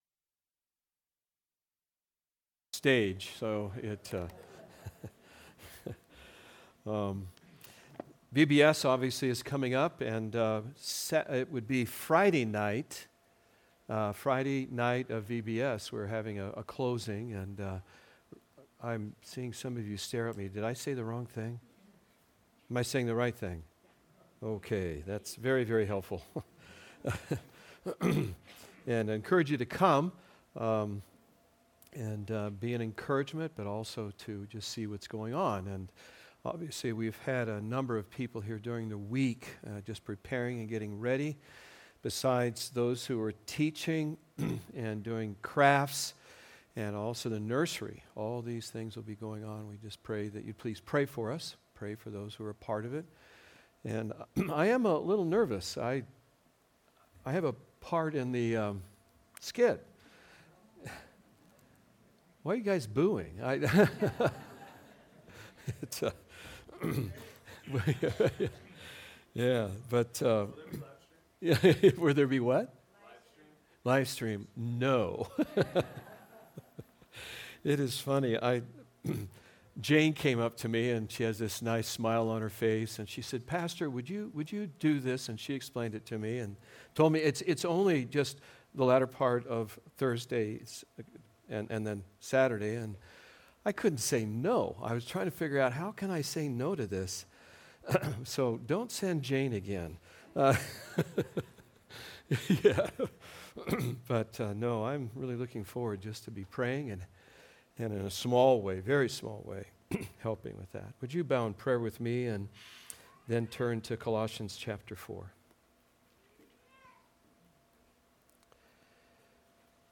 Our Sermons – Immanuel Baptist Church